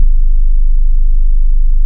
PLEXUS BASS.wav